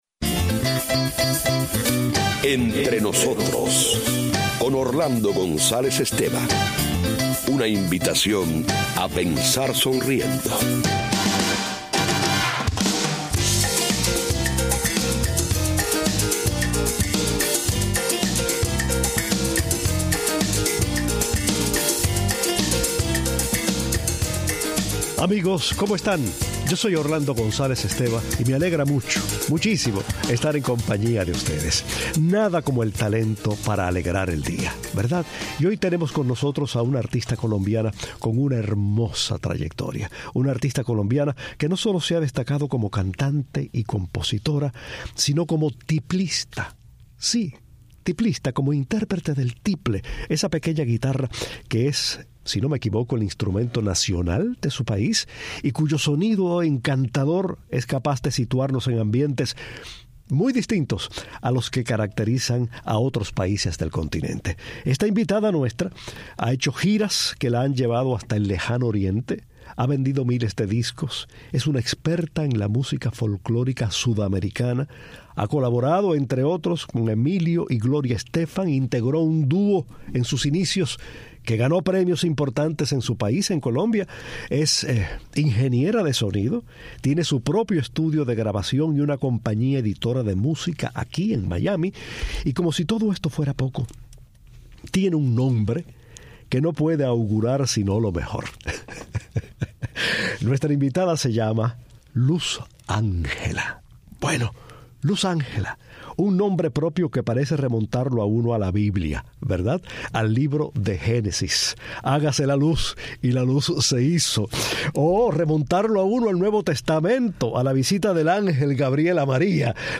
La encantadora cantante colombiana habla con pasión del instrumento nacional de su país, de su hermosa carrera como intérprete vocal de la música sudamericana y de sus giras por el mundo.